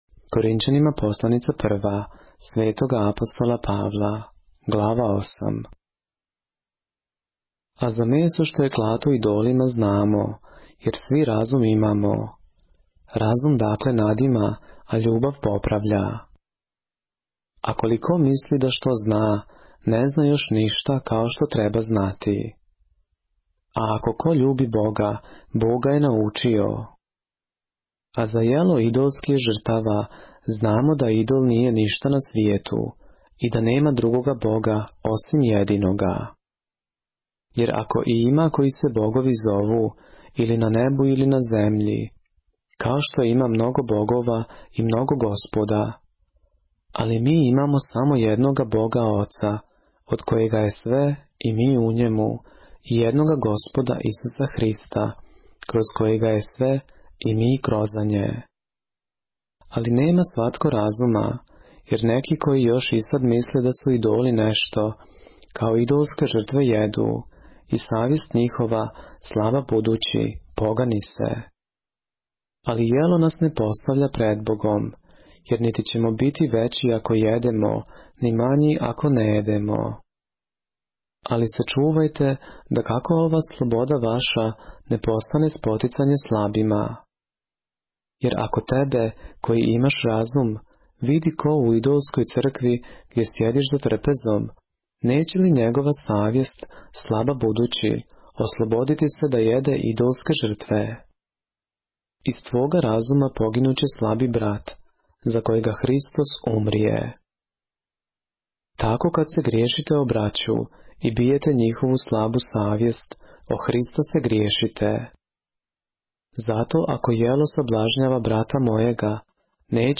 поглавље српске Библије - са аудио нарације - 1 Corinthians, chapter 8 of the Holy Bible in the Serbian language